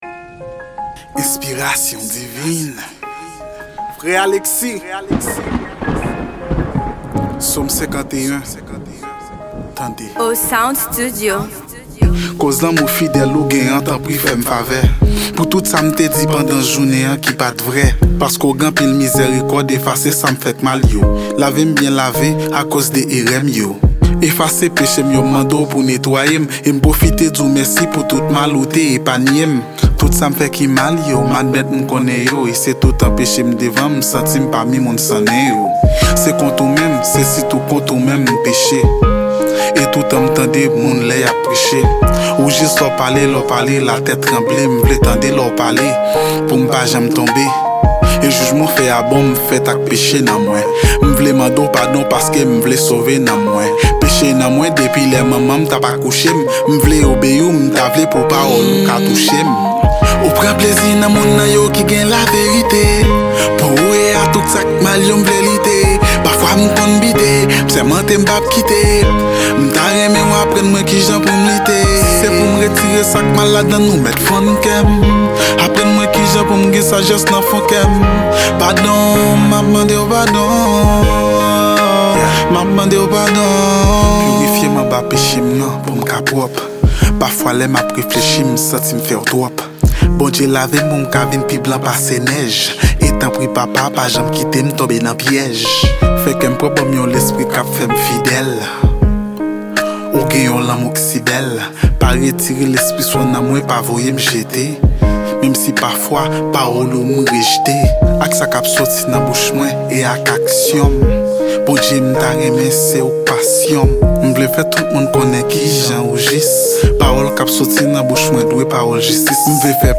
Genre: Rap Gospel.